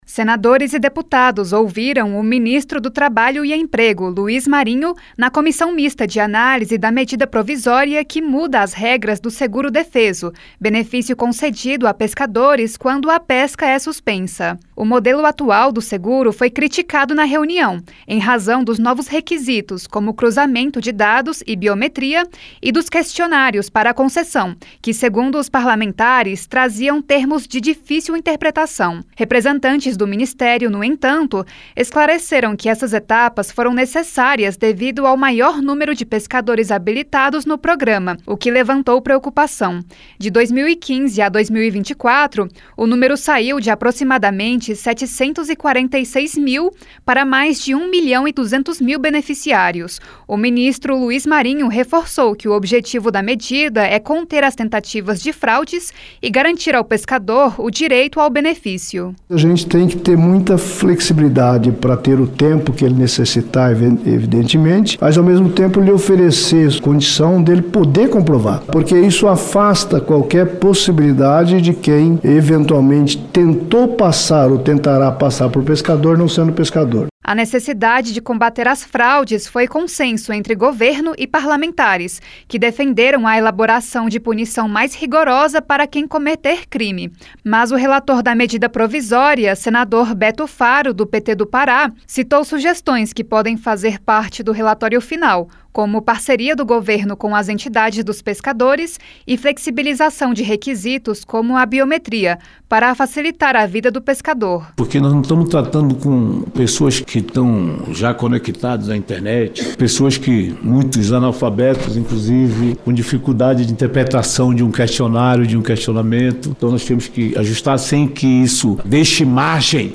Mudanças no seguro-defeso podem inibir fraudes, diz ministro em audiência